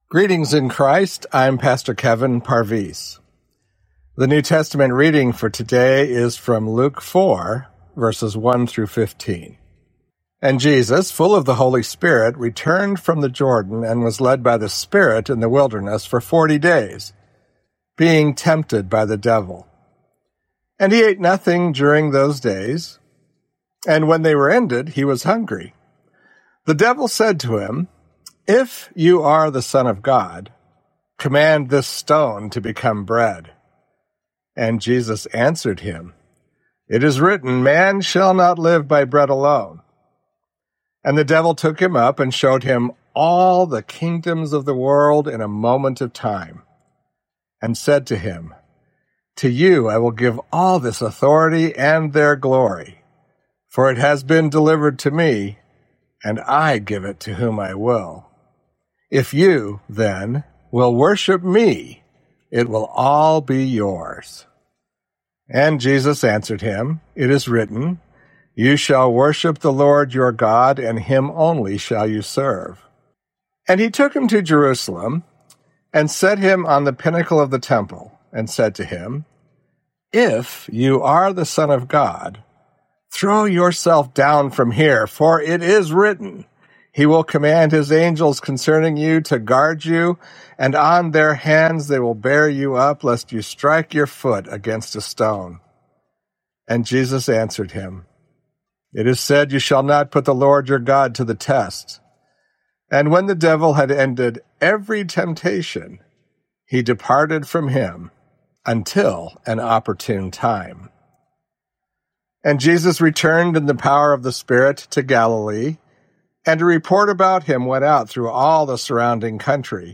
Morning Prayer Sermonette: Luke 4:1-15
Hear a guest pastor give a short sermonette based on the day’s Daily Lectionary New Testament text during Morning and Evening Prayer.